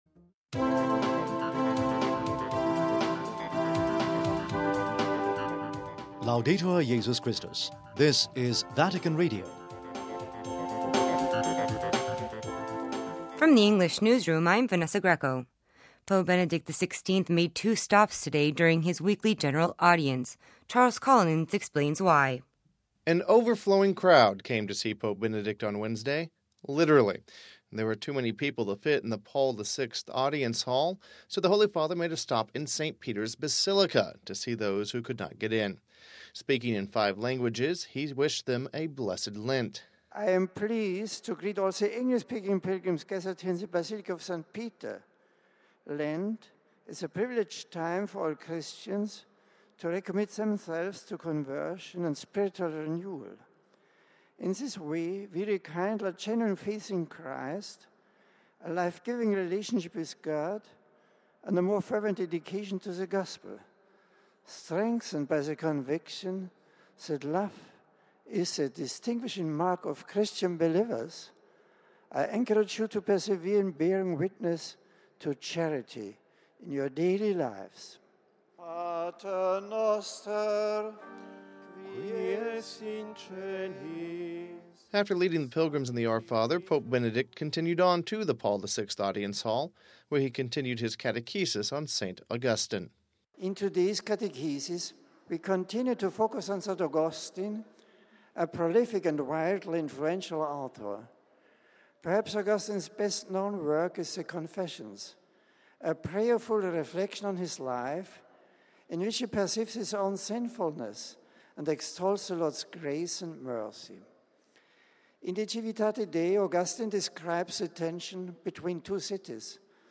( 20 Feb 08 - RV) Pope Benedict today continued his catechesis on the teachings of St. Augustine, the 5th century Church Father from Hippo. After speaking in Italian, he summarized his talk in English…